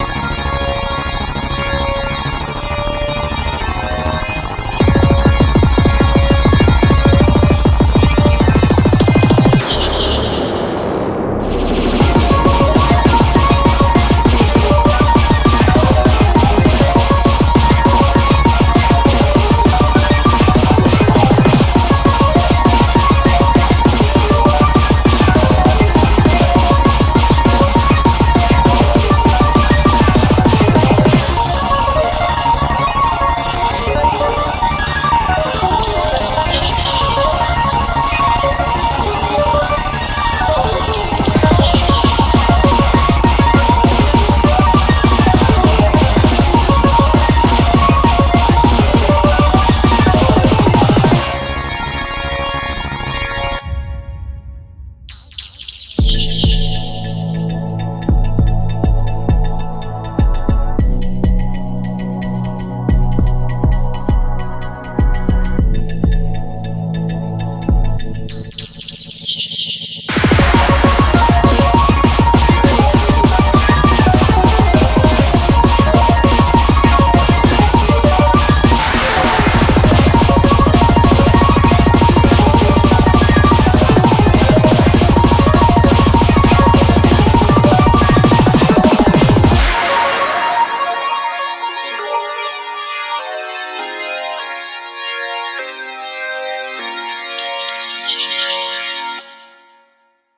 BPM99-400
Audio QualityPerfect (High Quality)